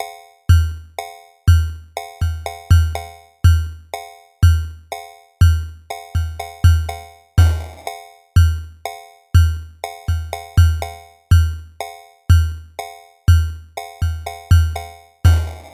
描述：一个奇怪的、类似于鼓的循环，由受影响的合成器组成，呈 "哔、啵、哔、啵、哔、啵 "的模式，最后有一个受影响的铙钹。
Tag: 122 bpm Weird Loops Synth Loops 2.65 MB wav Key : Unknown